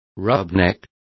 Complete with pronunciation of the translation of rubberneck.